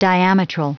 Prononciation du mot diametral en anglais (fichier audio)
Prononciation du mot : diametral